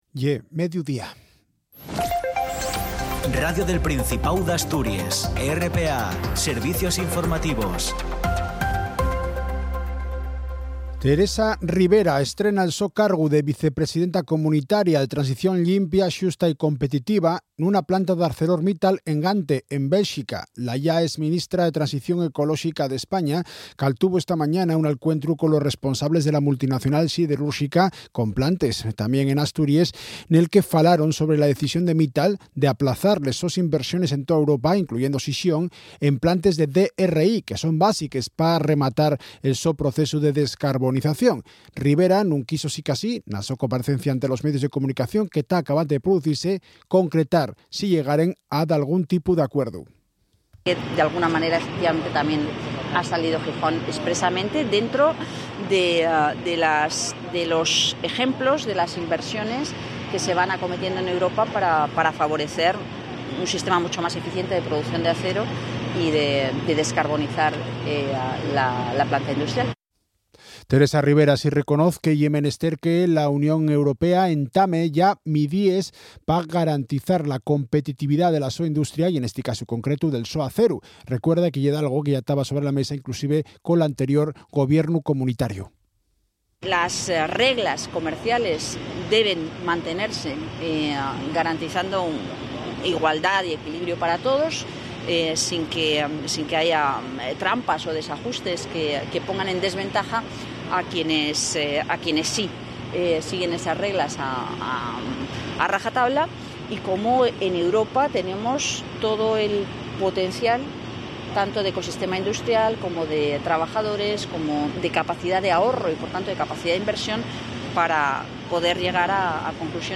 … continue reading 17 episodios # España # Radiotelevisión del Principado de Asturias (RTPA # Noticias Diarias # Países Bajos Noticias